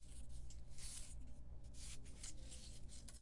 音频1 " 10 Contacto Flauta
描述：Foley Final Audio1 2018